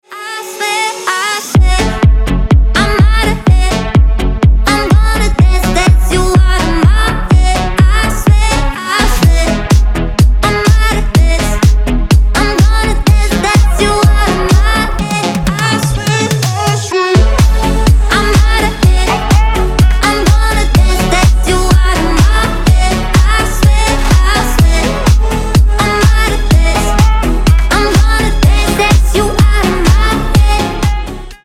• Качество: 320, Stereo
красивый женский голос
динамичные